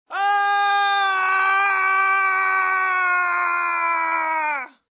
Descarga de Sonidos mp3 Gratis: grito 4.
descargar sonido mp3 grito 4